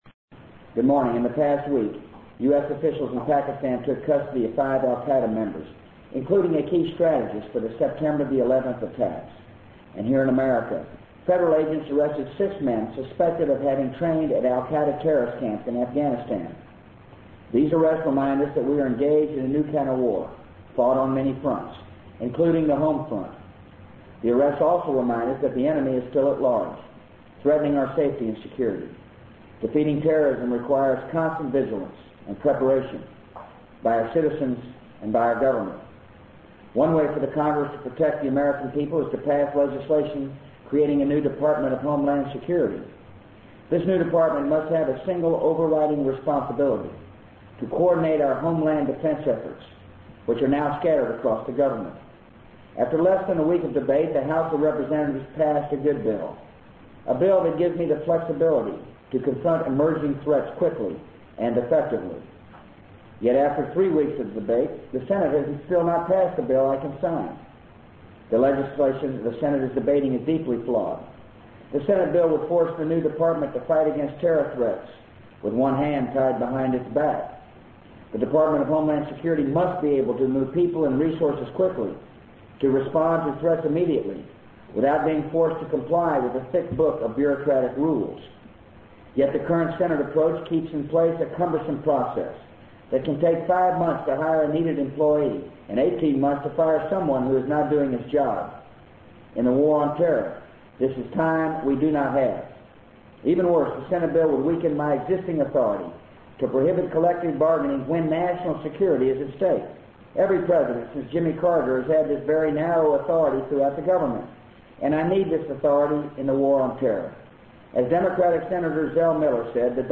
【美国总统George W. Bush电台演讲】2002-09-21 听力文件下载—在线英语听力室